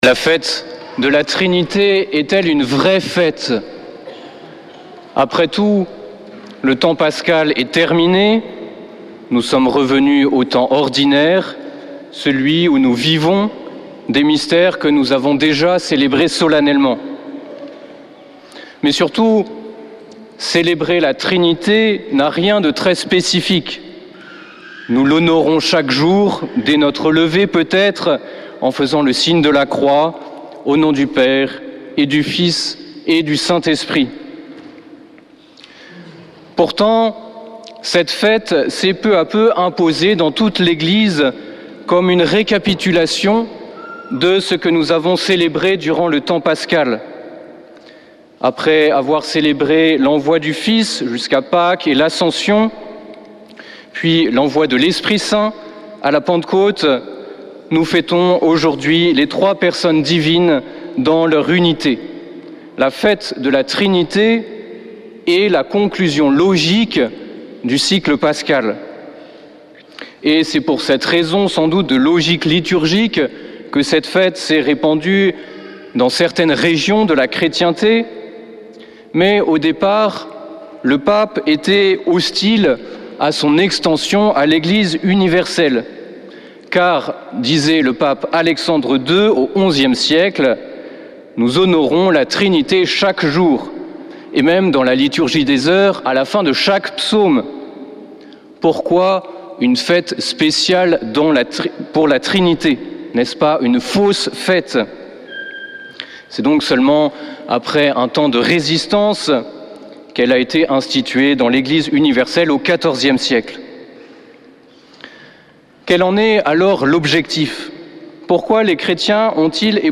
dimanche 26 mai 2024 Messe depuis le couvent des Dominicains de Toulouse Durée 01 h 30 min
Homélie du 26 mai